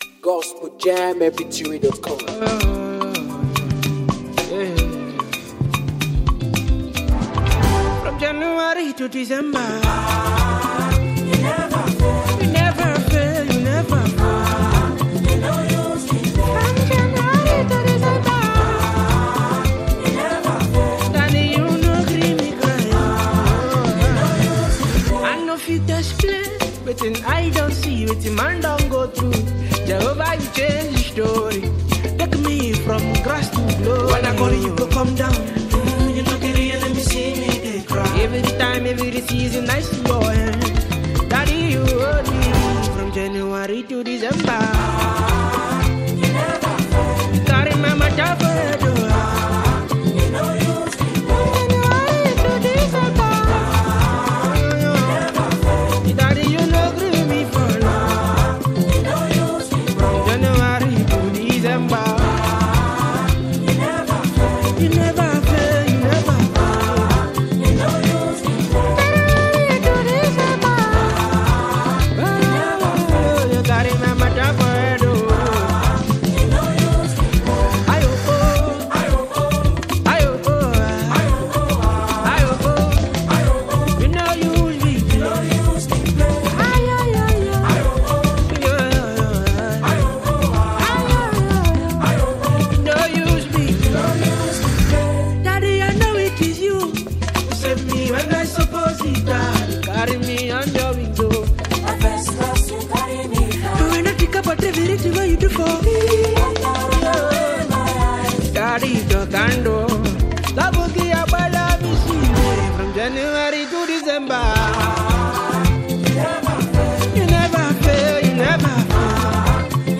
Afro beatmusic
a soulful, inspirational song